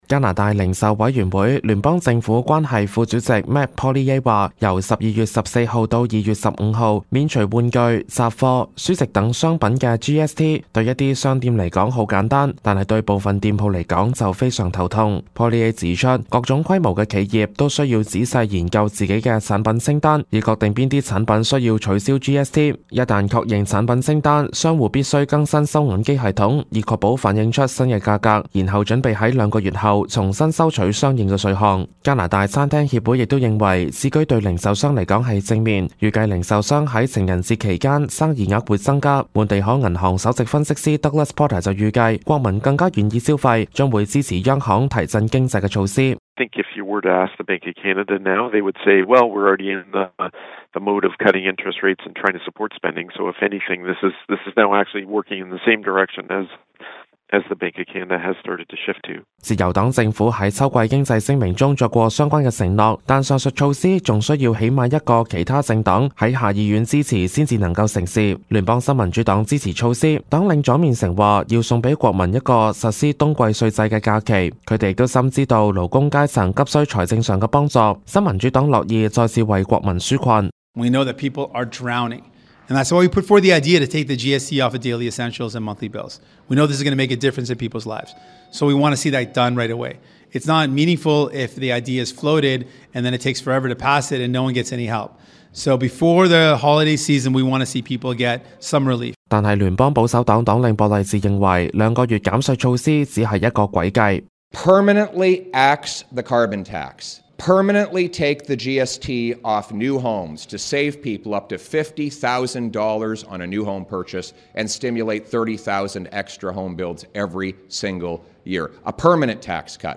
Canada/World News 全國/世界新聞
報道